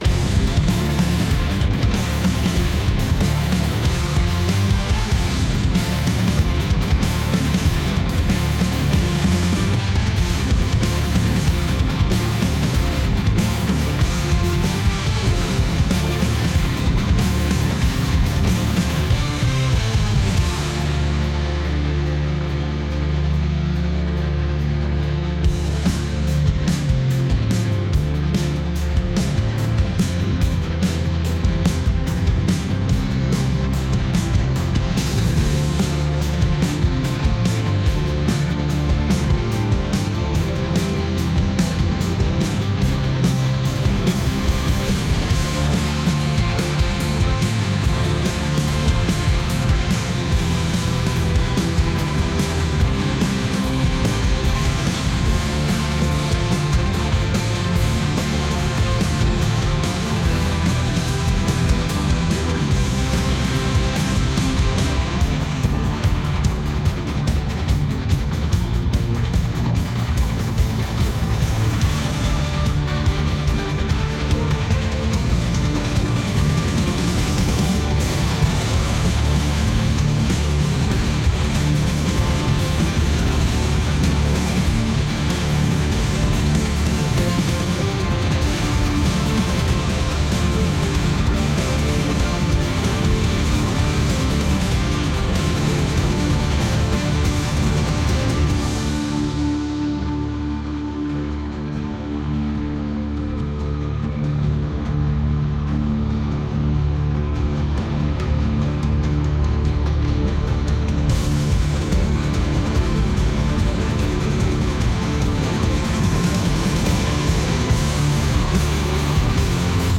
indie | rock